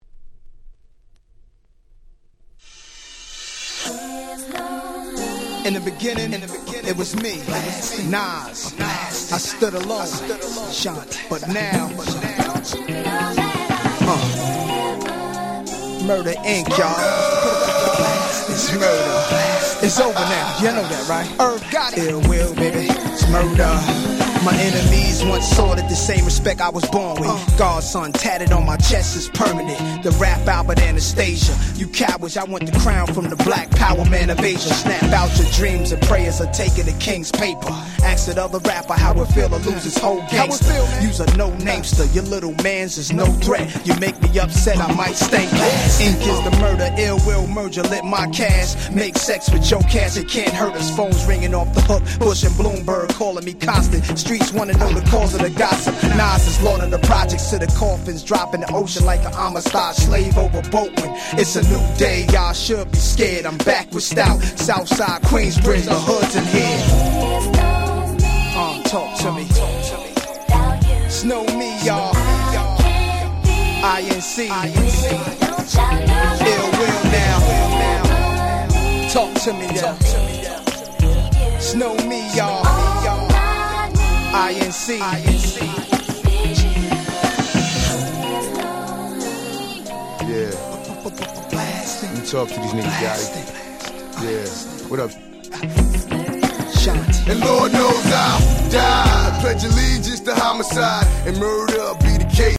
02' Smash Hit Hip Hop !!